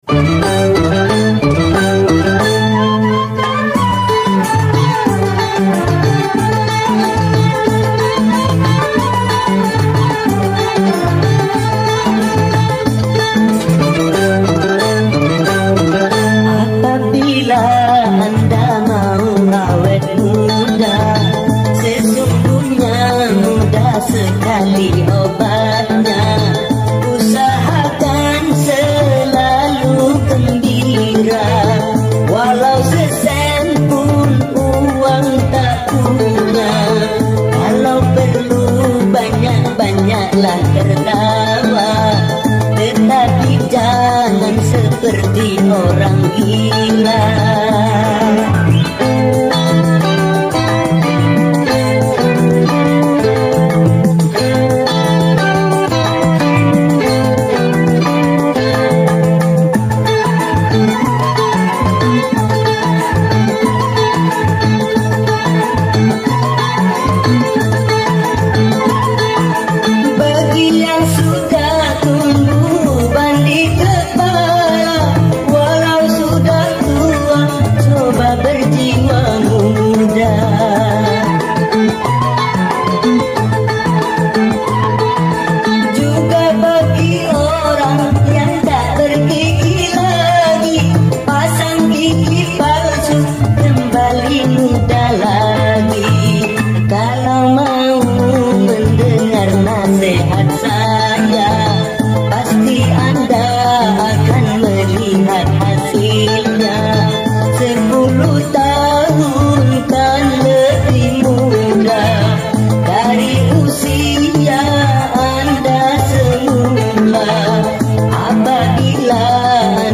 HD HQ stereo